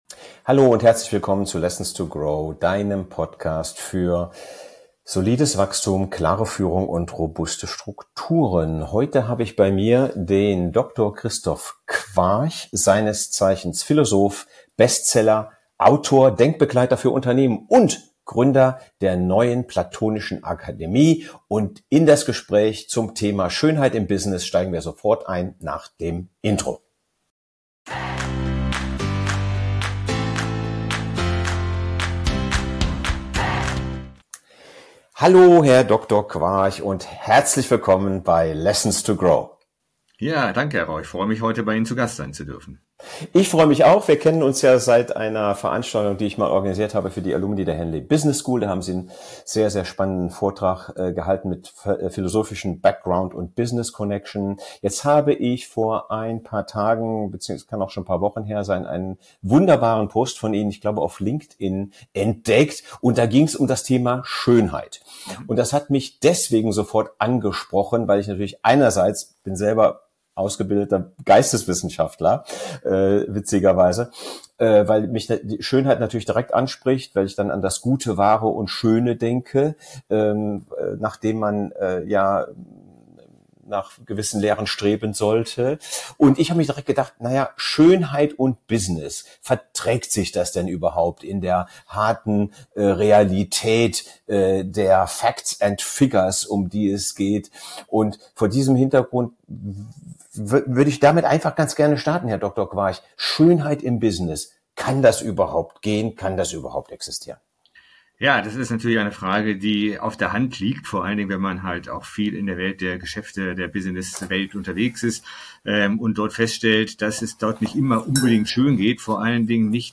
In dieser neuen Folge von Lessons to Grow wartet ein inspirierendes Gespräch auf Dich.